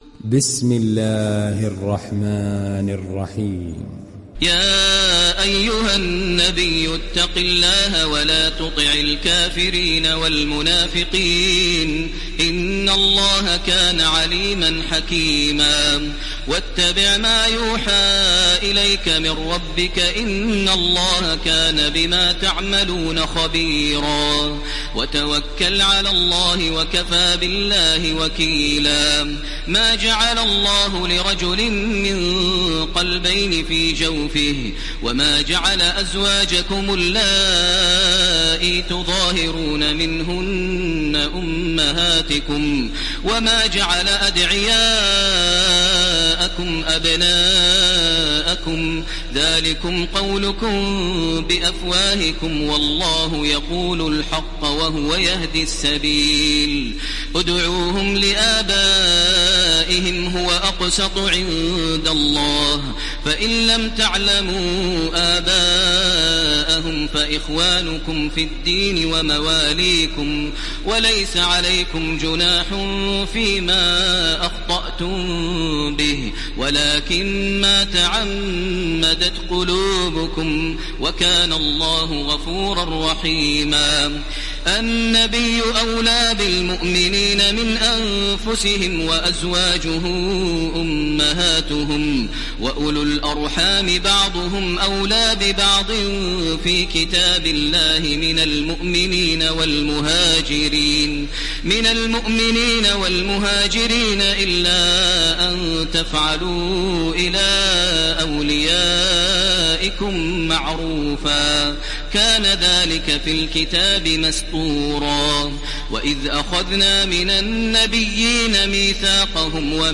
دانلود سوره الأحزاب تراويح الحرم المكي 1430